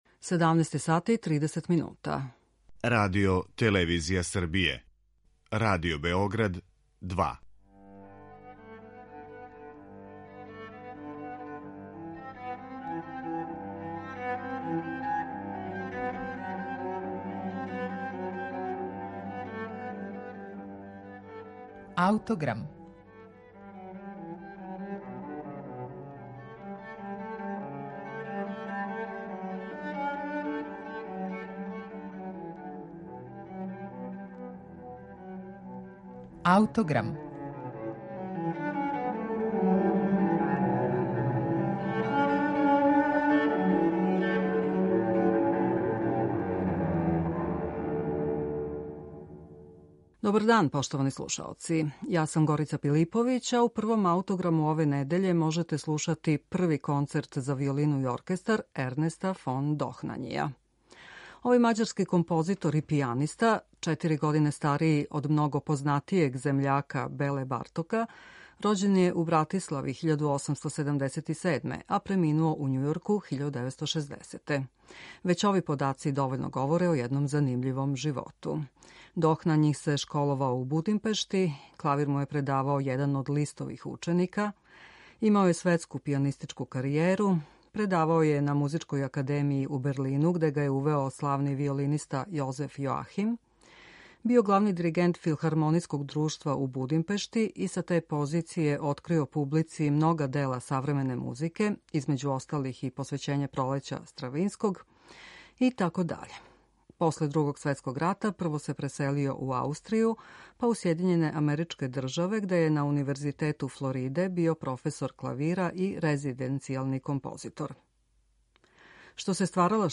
Мађарски композитор из прошлог века Ернест фон Дохнањи компоновао је, између осталог, два концерта за виолину и оркестар.
За разлику од својих великих савременика Беле Бартока и Золтана Кодаја, Дохнањи је остао чврсто укорењен у романтичарској традицији.